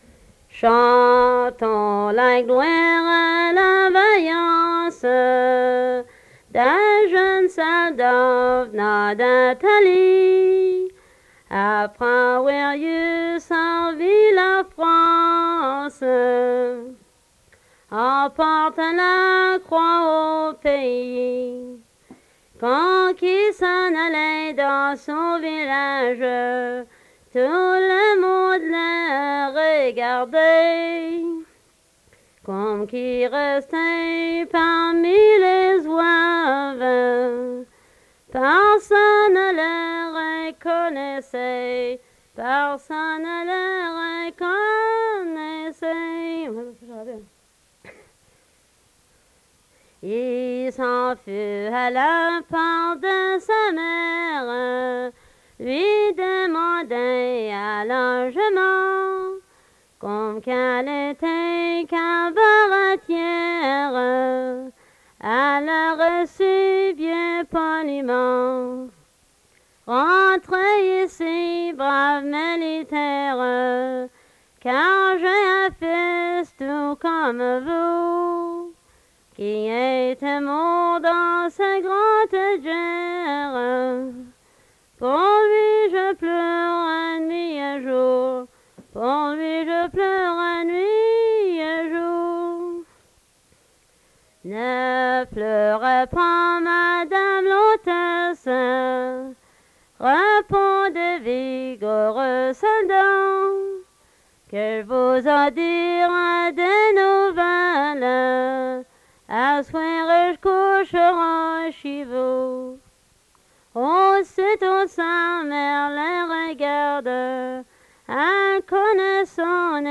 Emplacement Upper Ferry